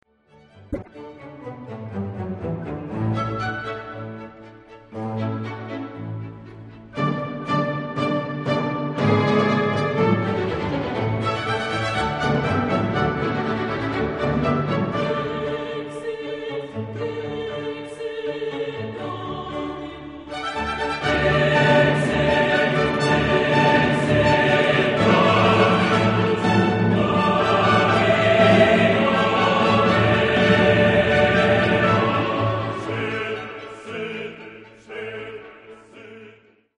Genre-Style-Form: Sacred ; Baroque ; Motet
Type of Choir: SATB + SATB OR SSAATTBB  (8 double choir OR mixed voices )
Soloist(s): SATB  (4 soloist(s))
Instrumentation: Orchestra
Tonality: D major
sung by Estonian Philharmonic Chamber Choir conducted by Tonu Kaljuste